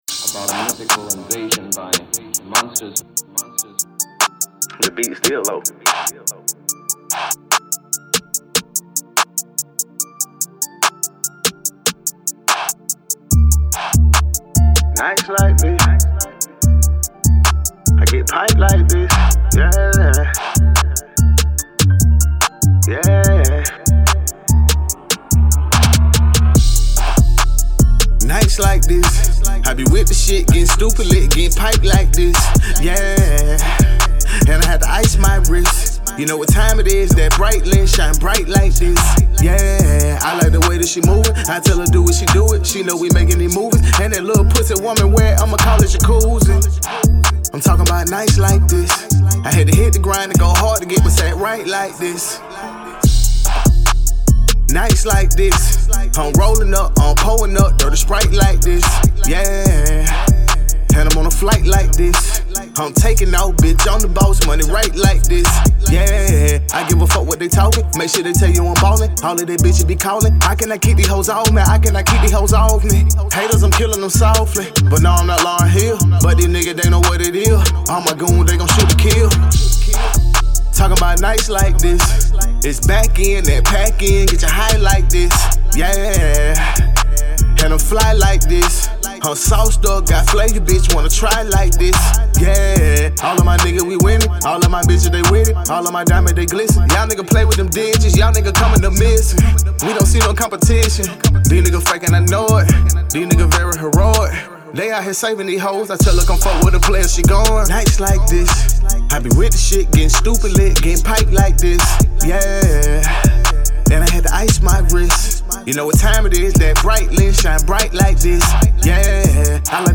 Hiphop
silky melodic keys & 808 bounce